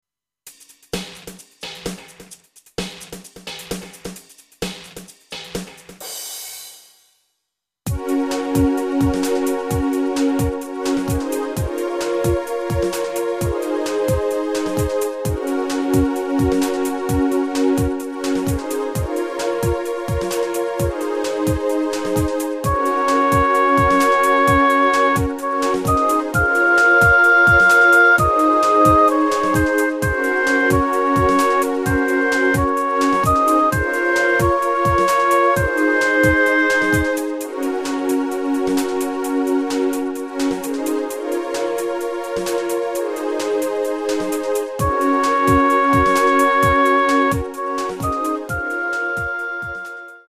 即興演奏みたいなものに挑戦してみました。